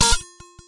描述：这是一个从垂直轴倾斜的旧玻璃瓶，因此它会以越来越高的频率前后摇动，直到它静止不动。记录在iphone上。
标签： 物理 定居 玻璃 加速
声道立体声